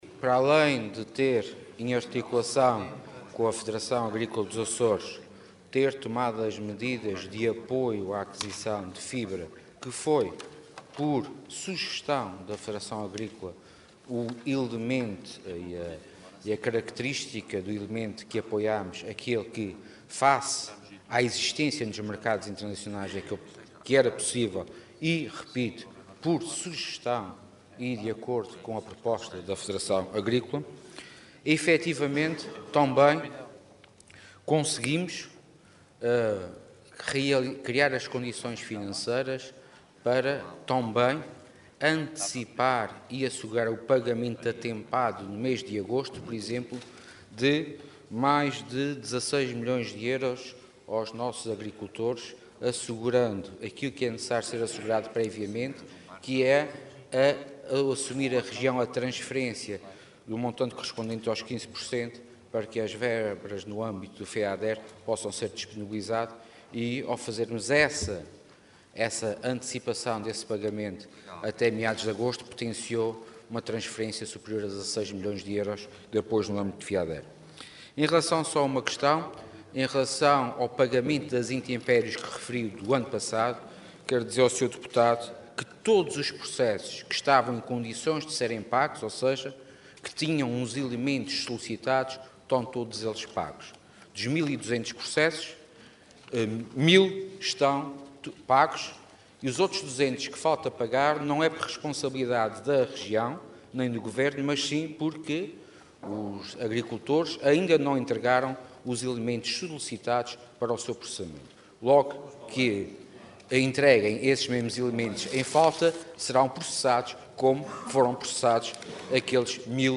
Sérgio Ávila, que falava na sessão plenária da Assembleia Legislativa, na Horta, recordou que, em articulação com a Associação Agrícola dos Açores e por sugestão desta, foi já apoiada a aquisição de fibra para suprir a escassez de alimento para o gado resultante da seca.